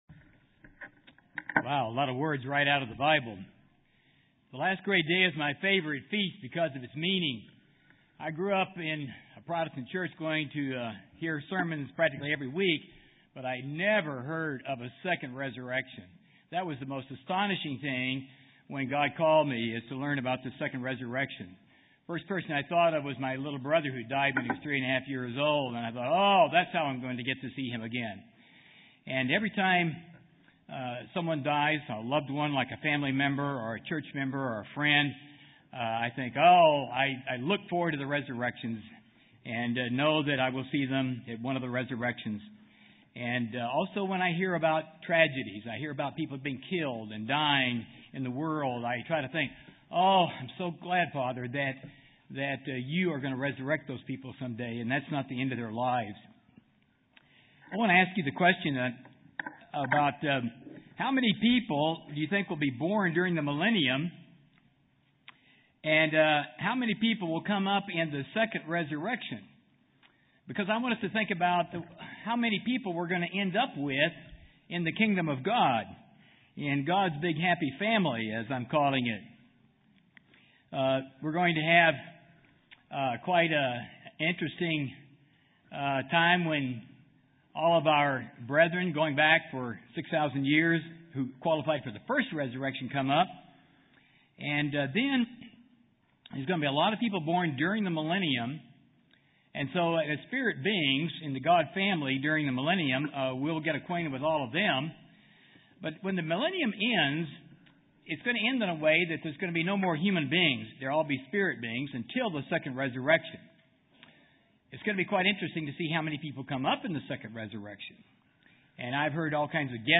This sermon was given at the Kelowna, British Columbia 2010 Feast site.